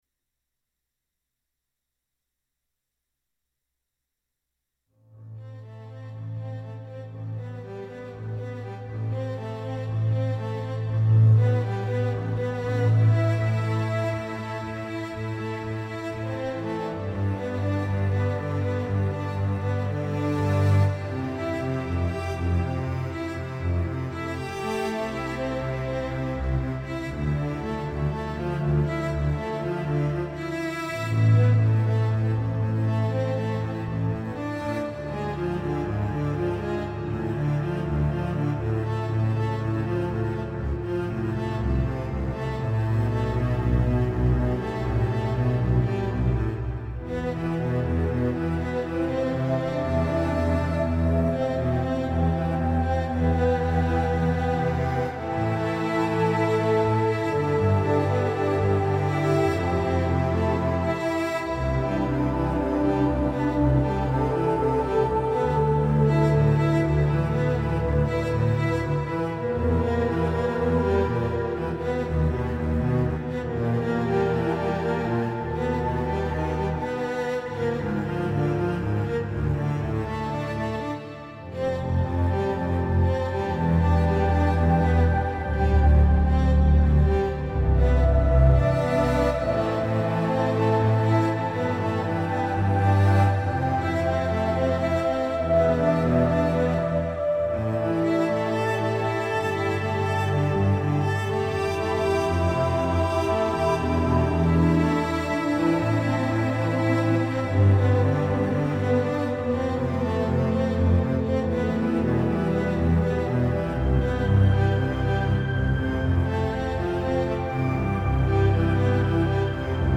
Cinematic/Symphonic Orchestral with Choir..